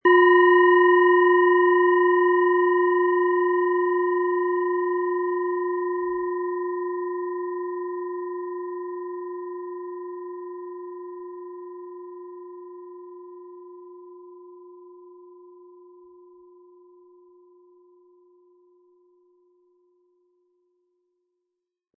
Planetenschale® Ins Fließen kommen & Umsetzen und in die Tat kommen mit Wasser-Ton, Ø 9,9 cm, 100-180 Gramm inkl. Klöppel
Diese tibetische Planetenschale Wasser ist von Hand gearbeitet.
Spielen Sie die Schale mit dem kostenfrei beigelegten Klöppel sanft an und sie wird wohltuend erklingen.
MaterialBronze